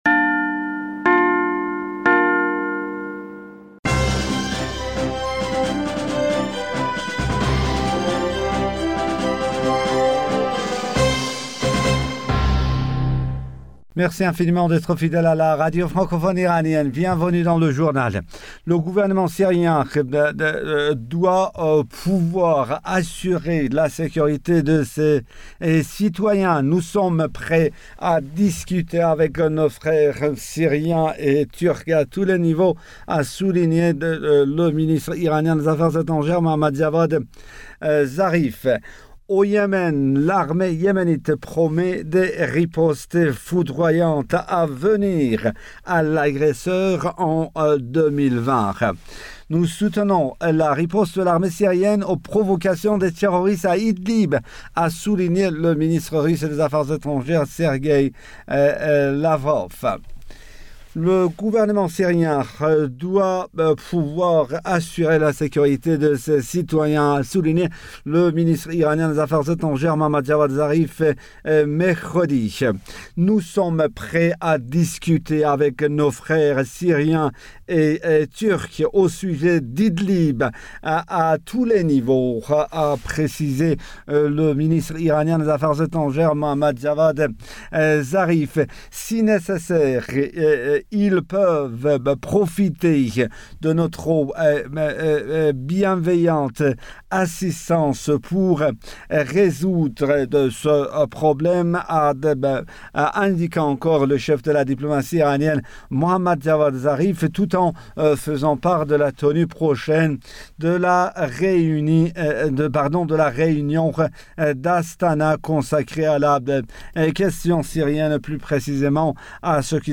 Bulletin d'information du 19 février 2020